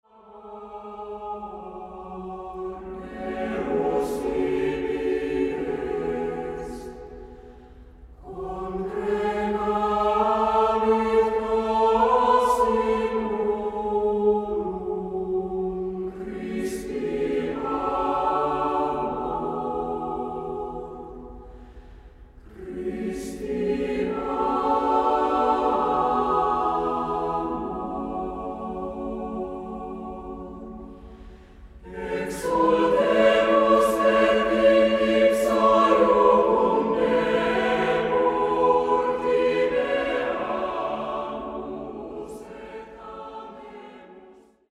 Levy sisältää erilaisiin hetkiin sopivia laulettuja rukouksia. Säestyksettömien laulujen rinnalla sointumaailmaa rikastavat jousi- ja urkusäestykselliset teokset.